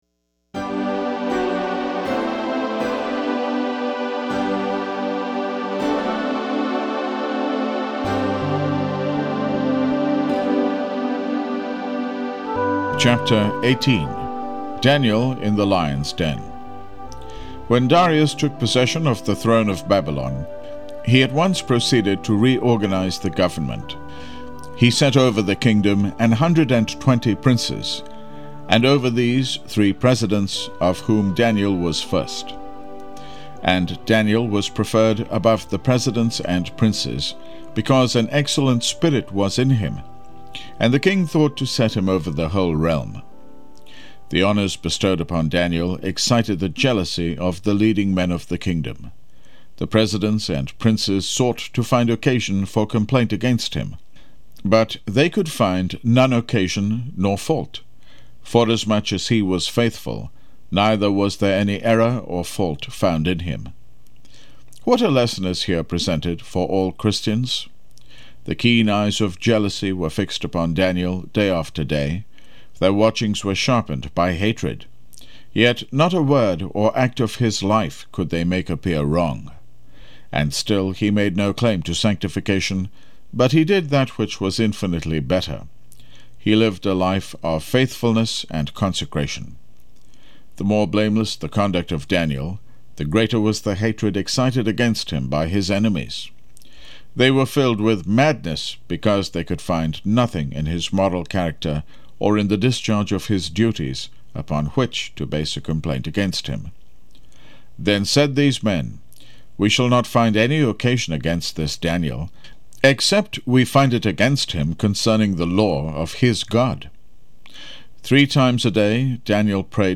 Steps to Christ for a Sanctified Life MP3 Audio Book